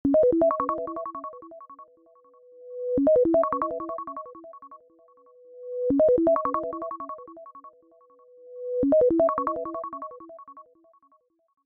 Unique Soft Unique Ring Ring Ring Ring